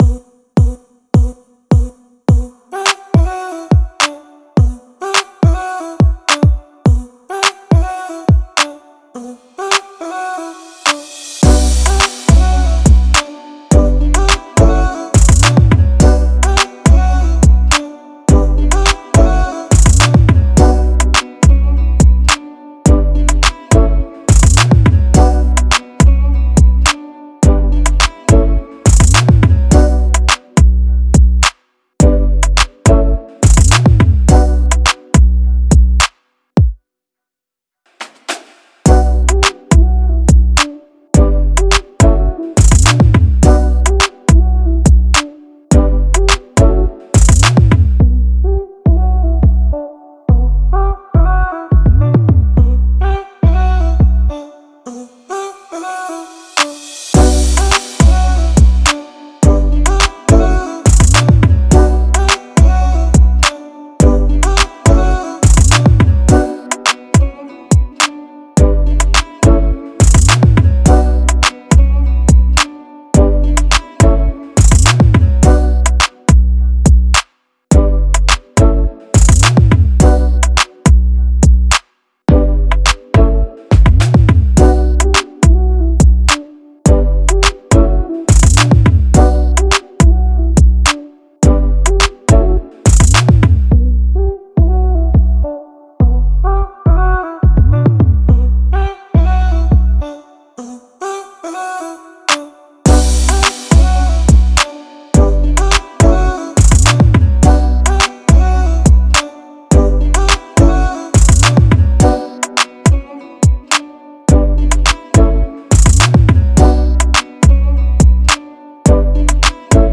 Audiofile Instrumentaal